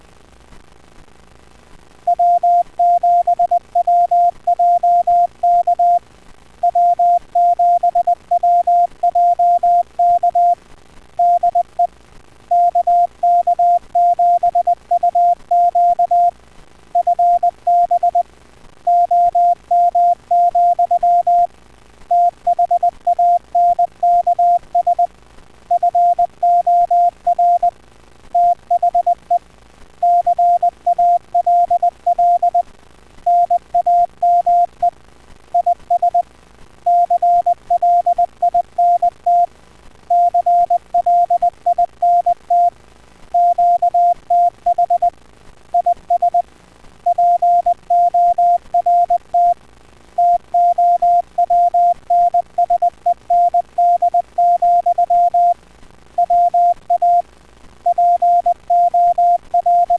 Ecco di seguito come suonano i vari modi:
cw 20 parole al minuto
cw20wpmA.wav